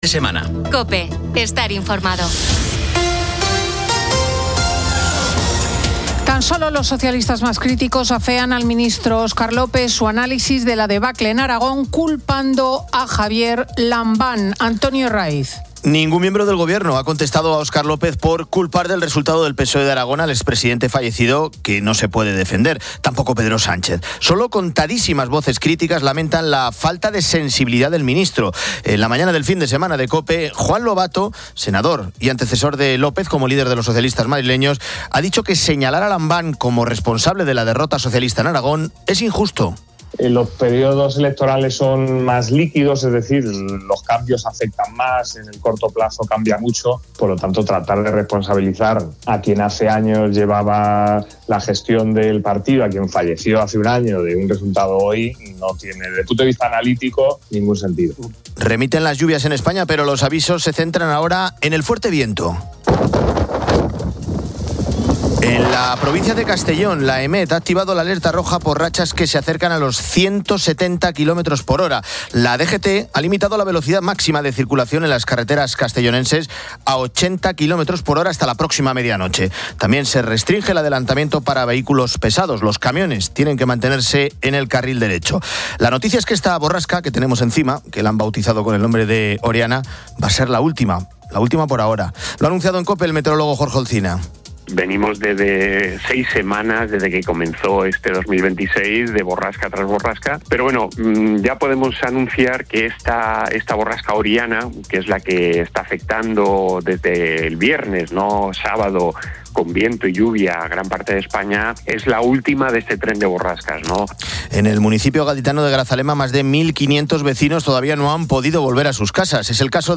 Fin de Semana 10:00H | 14 FEB 2026 | Fin de Semana Editorial de Cristina López Schlichting. Repasamos la actualidad con Antonio Jiménez.